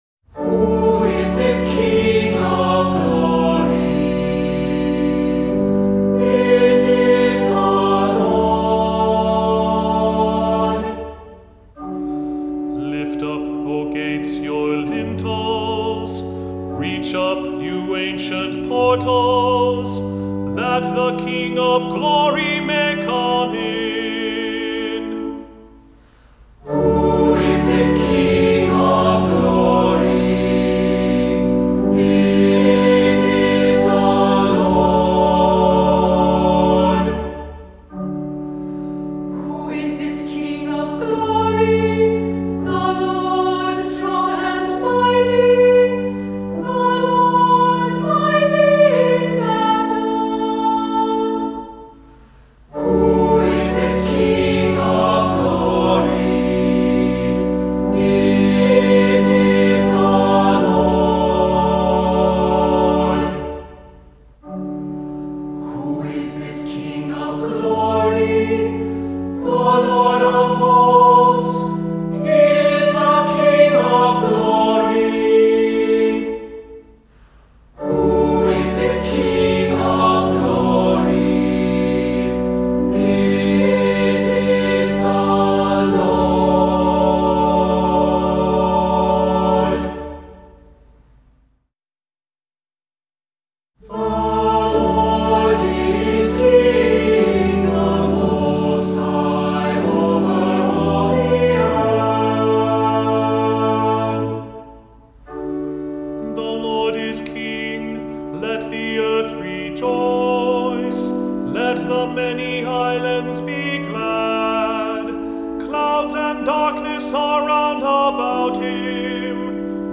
Voicing: SATB,Assembly,Cantor